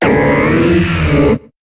Cri de Sepiatroce dans Pokémon HOME.